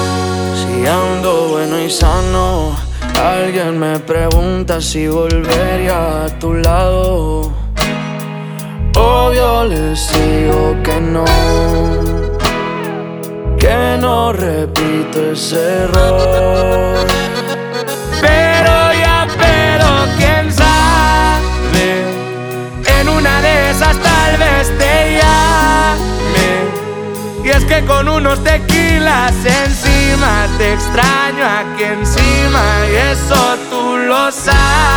# Regional Mexican